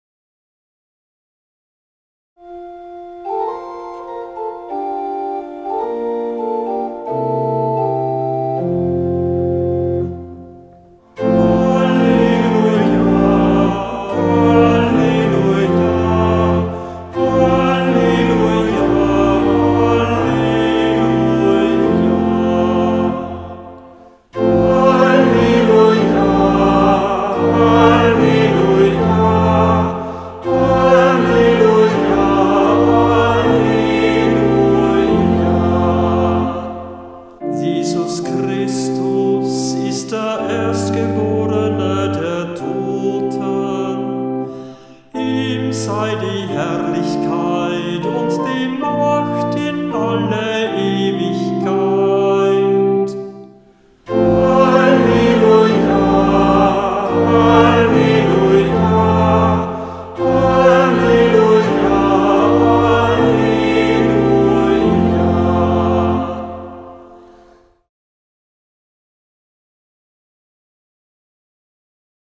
Ruf vor dem Evangelium - November 2025
Hörbeispiele aus dem Halleluja-Büchlein
Kantor wenn nicht anders angegeben